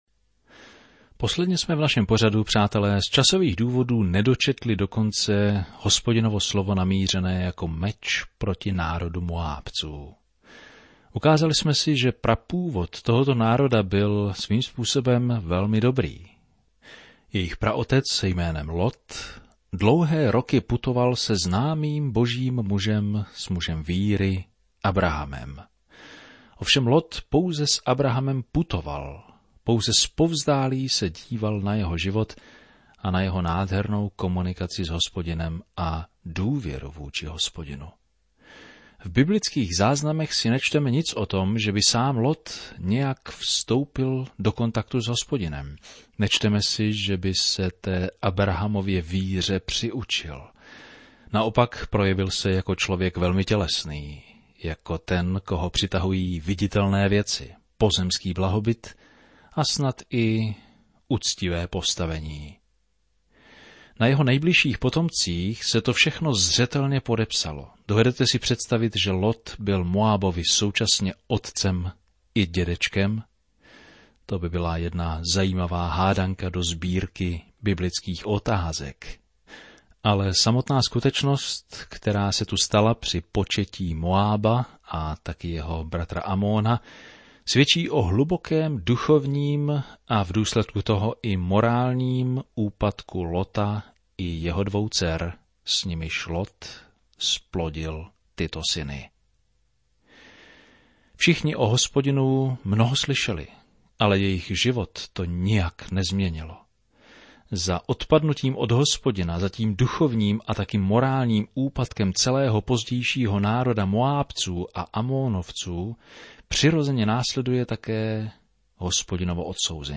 Je zamýšlen jako každodenní 30ti minutový rozhlasový pořad, který systematicky provádí posluchače celou Biblí.